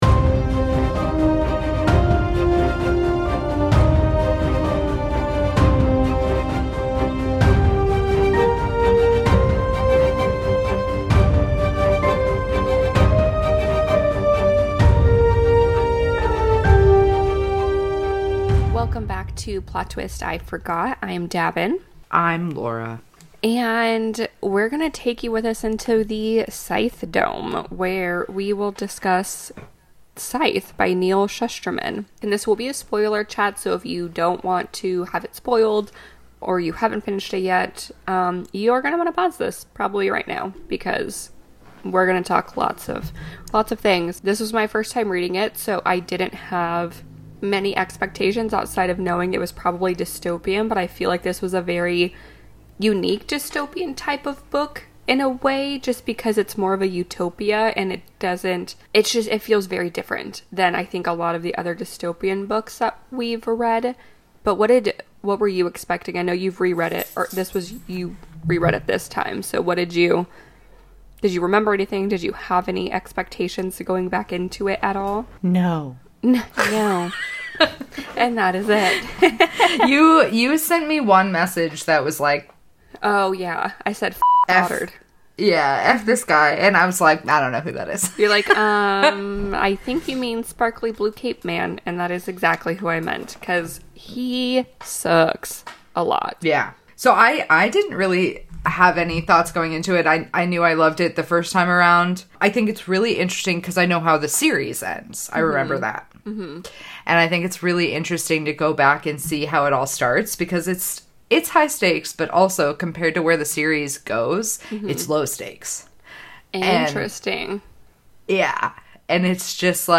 Today’s spoiler chat is all about Scythe by Neal Shusterman.